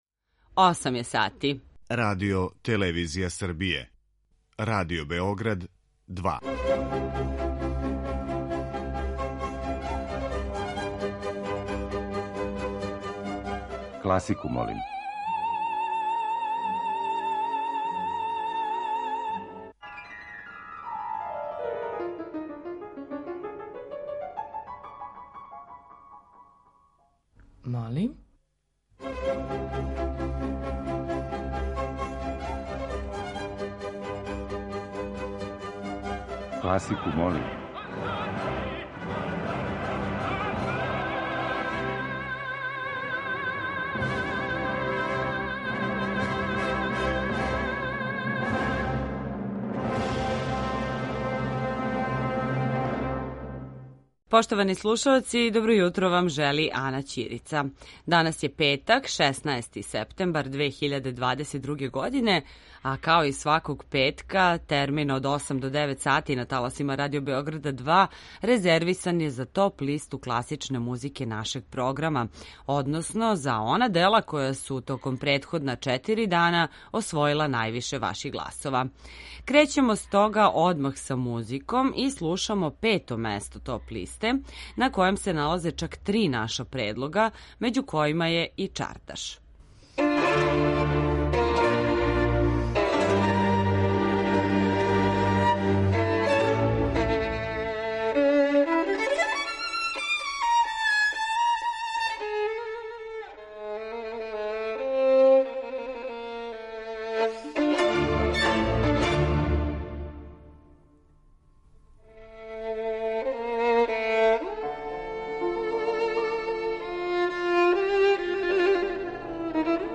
После сабирања гласова које смо примили од понедељка до четвртка, емитујемо топ листу класичне музике Радио Београда 2, односно композиције које су се највише допале слушаоцима.
klasika.mp3